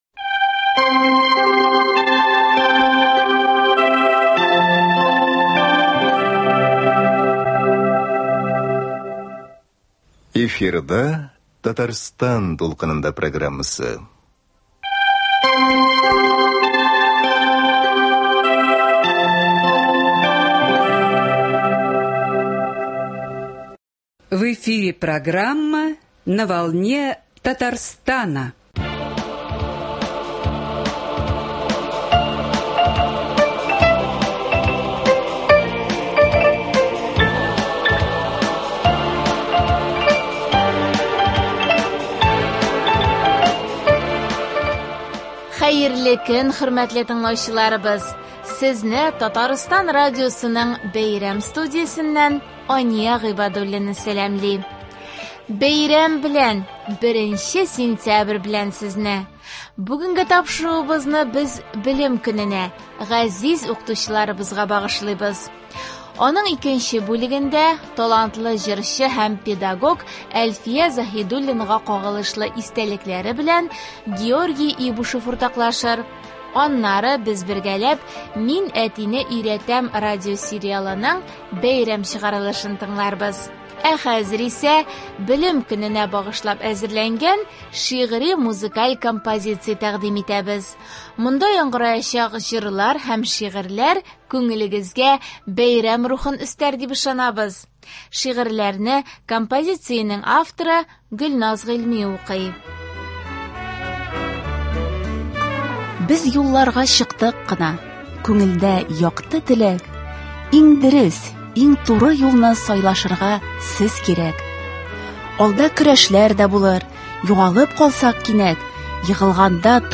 Ә хәзер исә Белем көненә багышлап әзерләнгән шигъри-музыкаль композиция тәкъдим итәбез. Монда яңгыраячак җырлар һәм шигырьләр күңелегезгә бәйрәм рухын өстәр дип ышанабыз.